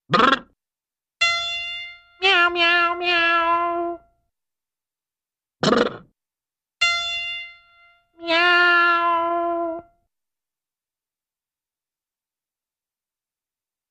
Cat Tuning Up, Throat Clear, Piano Note Followed By Musical Meow, 2 Versions